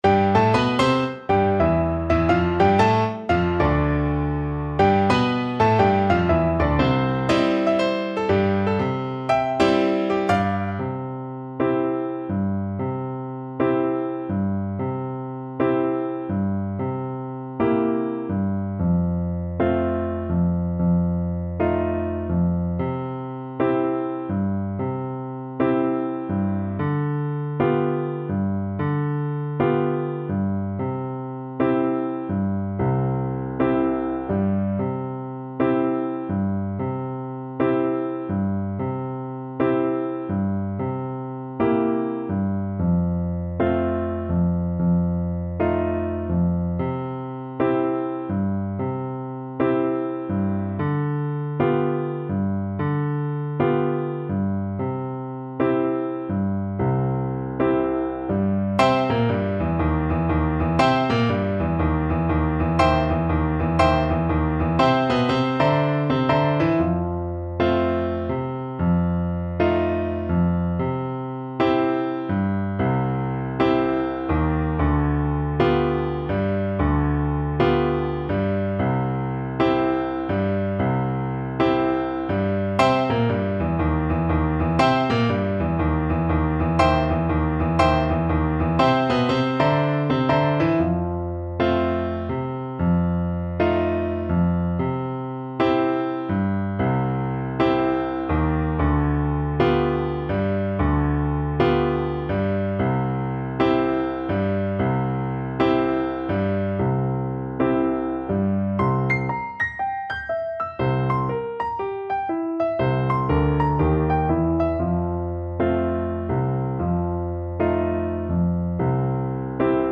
Moderato = 120
Jazz (View more Jazz Flute Music)